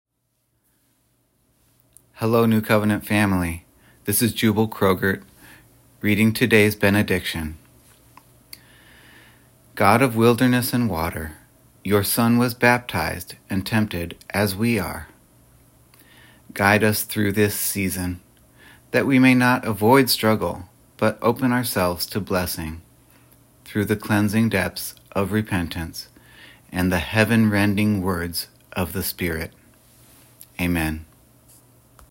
Benediction
Benediction-Mar-23-1.wav